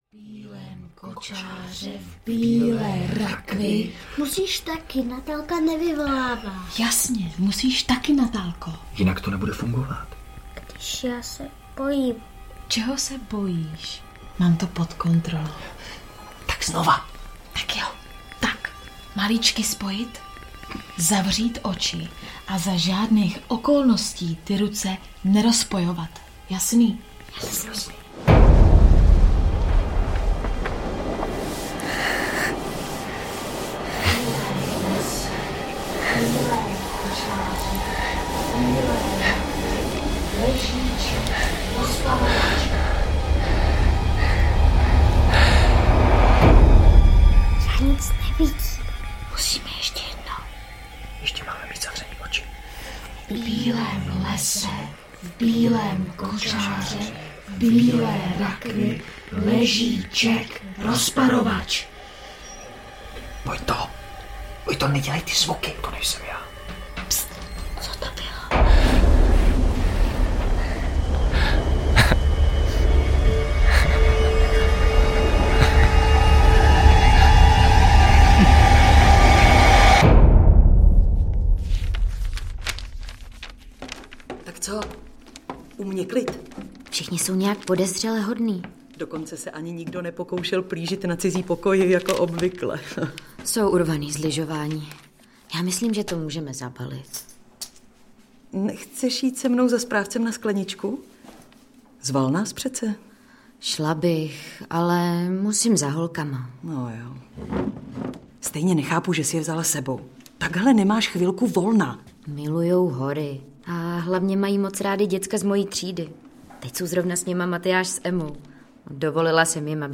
Lyžák audiokniha
Ukázka z knihy
Všechny hlasy a zvuky tak uslyšíte prostorově a plasticky, jako by postavy stály třeba přímo za vámi.